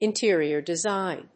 intérior desígn [decorátion]